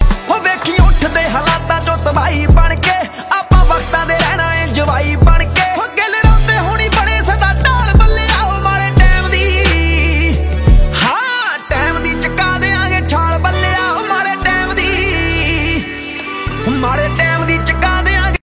PUNJABI SONG RINGTONE for your mobile phone in mp3 format.